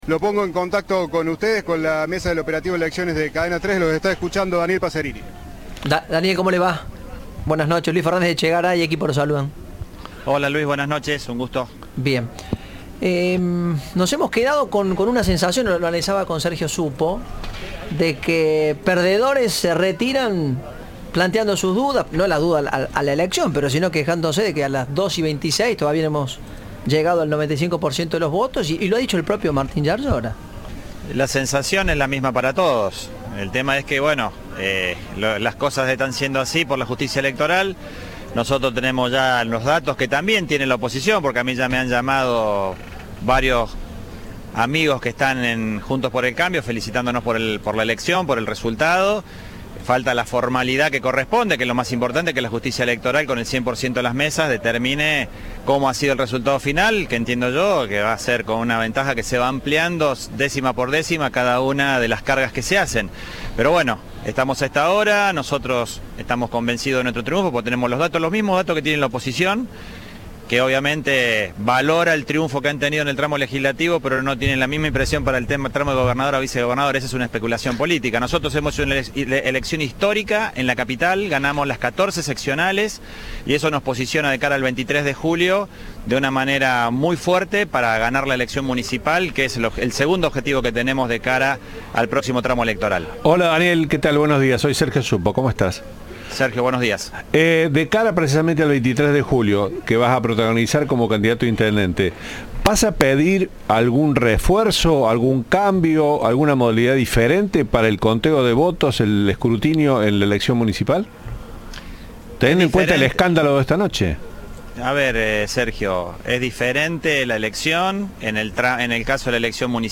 "Nuestro objetivo era ganar la capital. No pensamos que íbamos a ganar todas las seccionales y nos sorprendió para bien", resaltó en diálogo con Cadena 3.